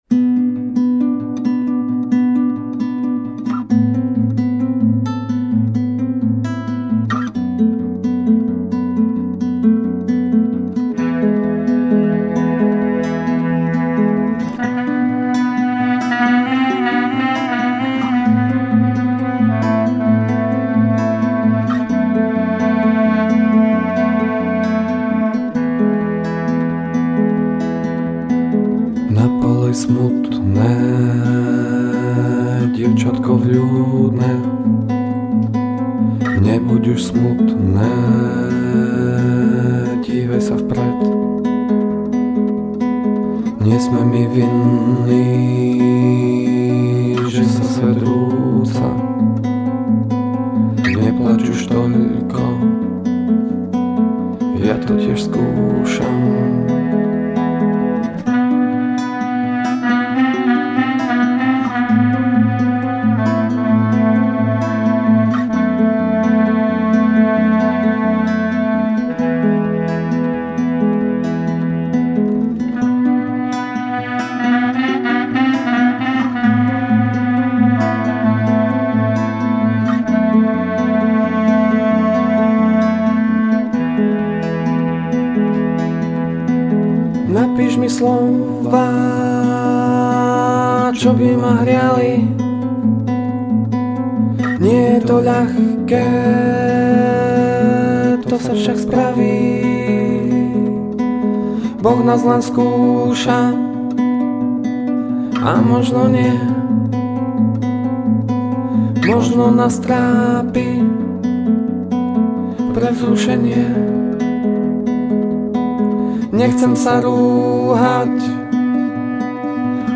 drums and percussions
el. guitars
keyboards
...and me :o) - voc, guitars, bassguitars, fluets, clarinets